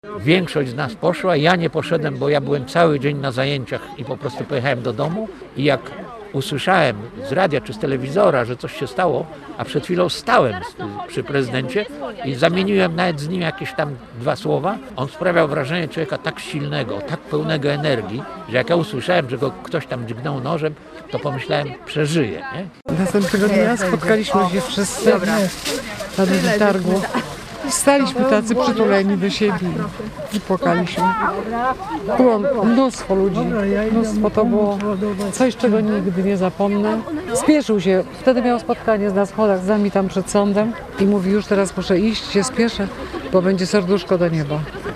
Wspomnienie-o-P.-Adamowiczu-mieszkancy.mp3